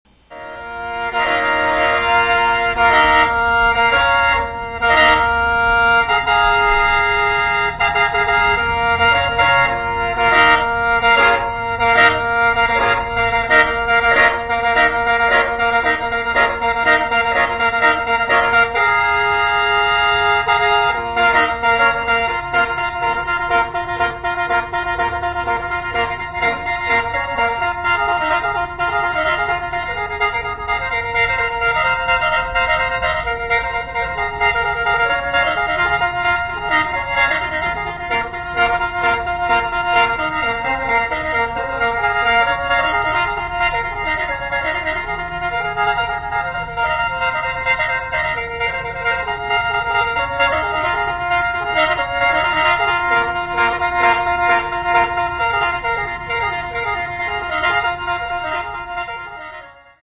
L’orgue à bouche
Le khène se prête parfaitement à la polyphonie et est peut-être le modèle le plus évolué de sa famille.
973-Khaen-ou-Khene-Laos.mp3